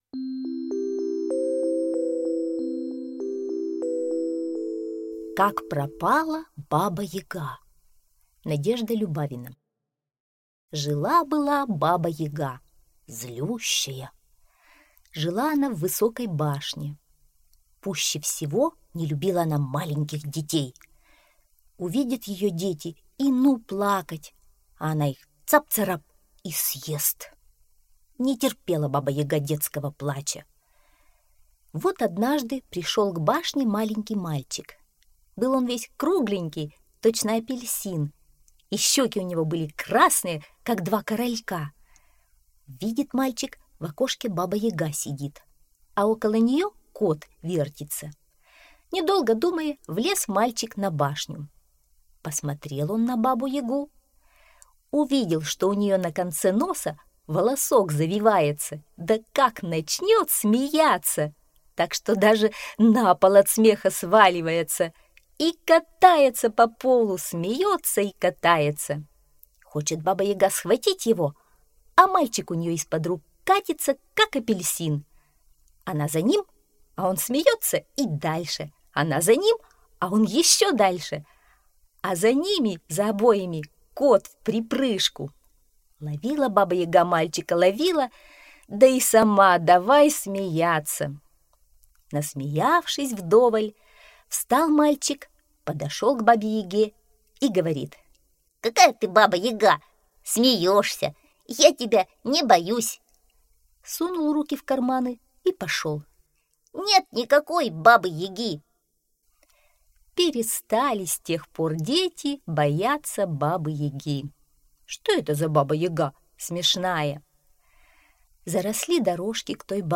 Как пропала Баба Яга - аудиосказка Любавиной - слушать онлайн